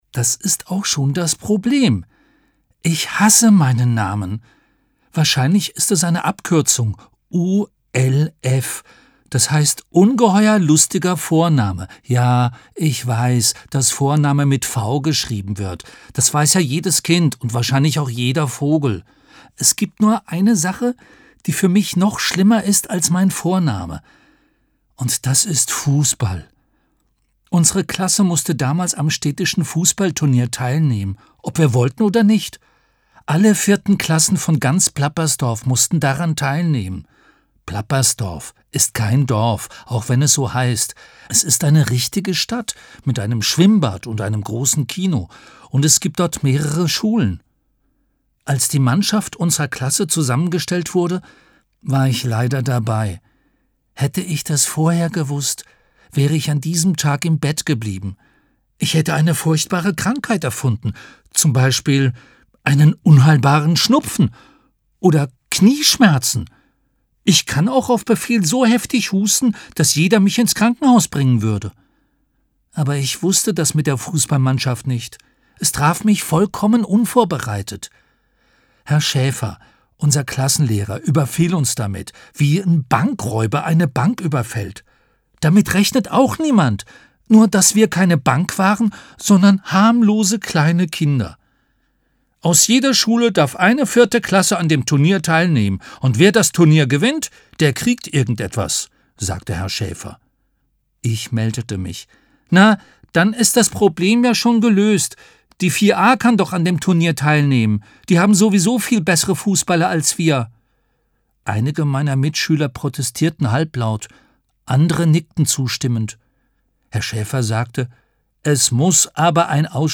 Mitarbeit Sprecher: Rufus Beck